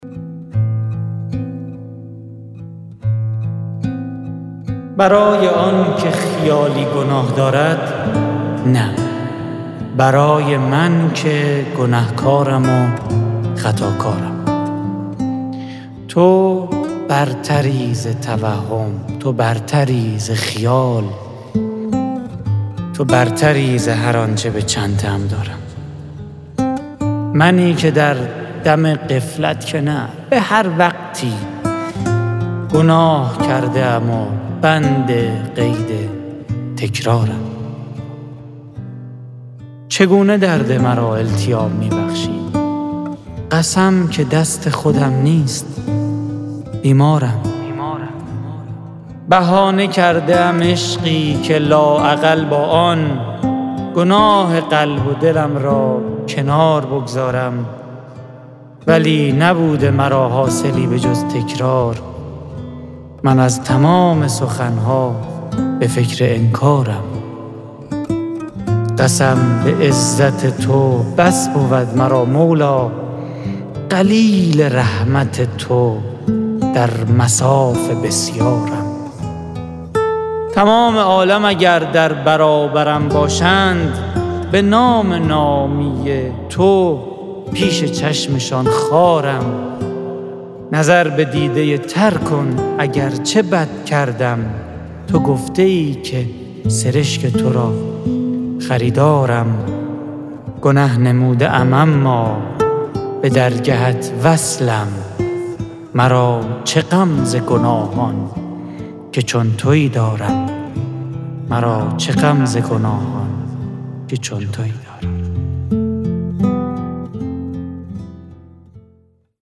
تهیه شده در استودیو